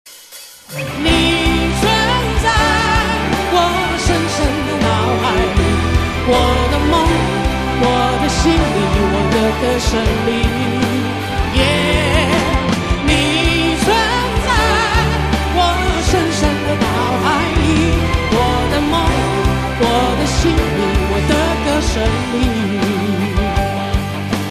M4R铃声, MP3铃声, 华语歌曲 122 首发日期：2018-05-16 00:13 星期三